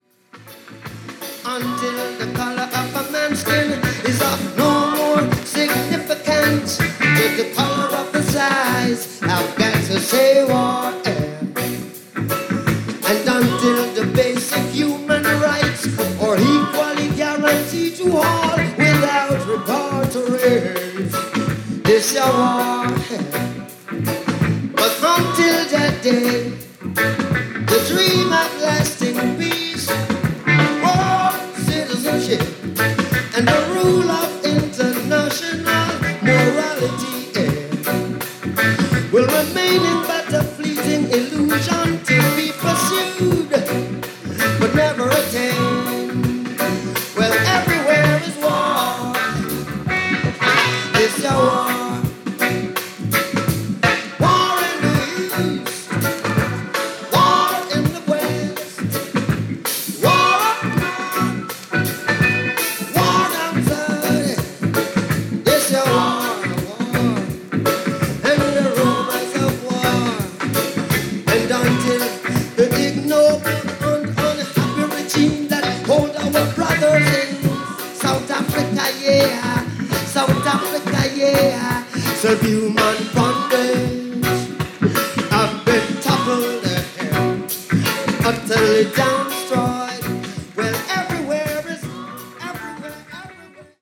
Live Versionで収録された 12"です。